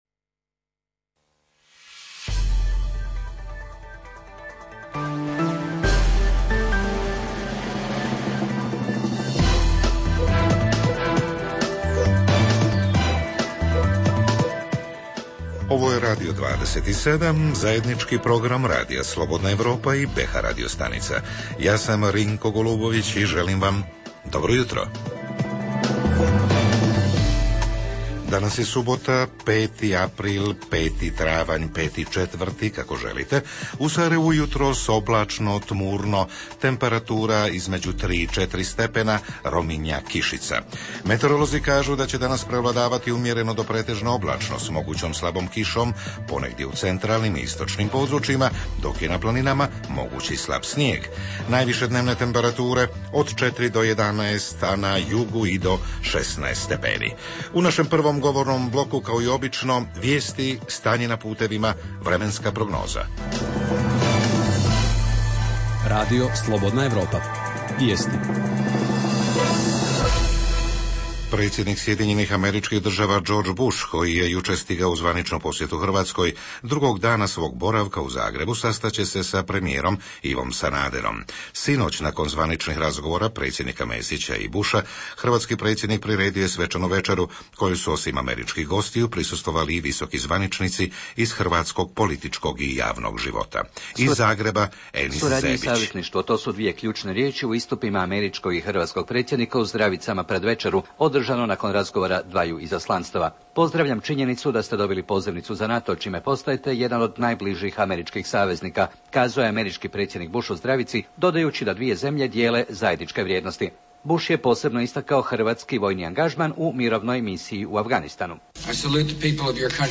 Jutarnji program za BiH koji se emituje uživo. Zajednička tema ovog jutra je: da li je vrijeme popravke uređaja kao što su TV, radio, usisivač, telefon, mali kućanski aparati, satovi, a da ne govorimo o kišobranima....prošlo!?
Redovni sadržaji jutarnjeg programa za BiH su i vijesti i muzika.